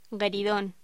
Locución: Gueridon